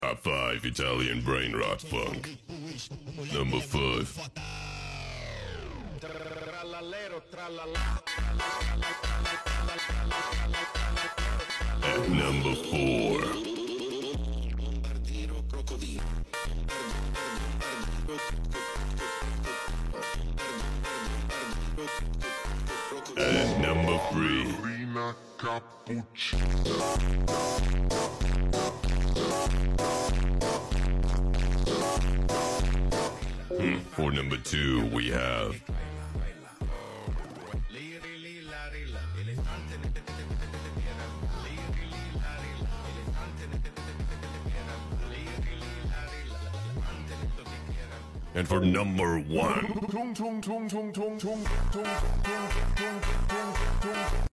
Top five italian brainrot funks sound effects free download